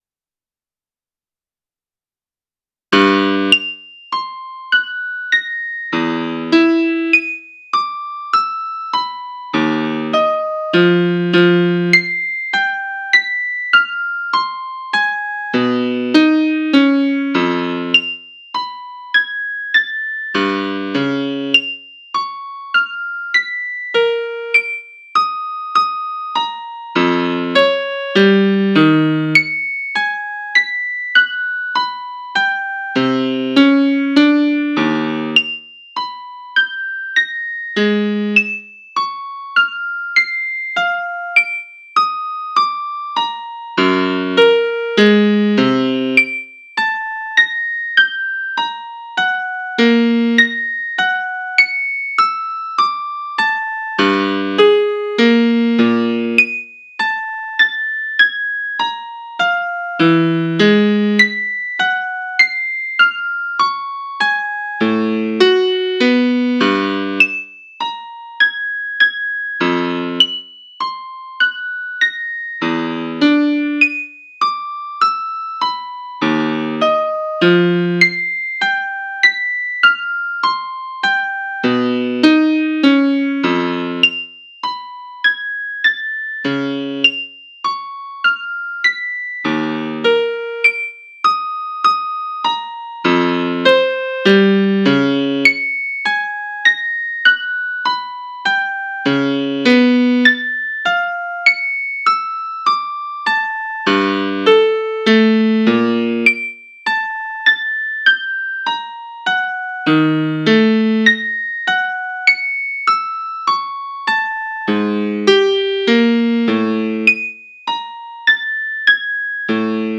Music 2 : (Slower pace): Lower pitch represents the lower distance between 2 bounces)
1noteper06sec.wav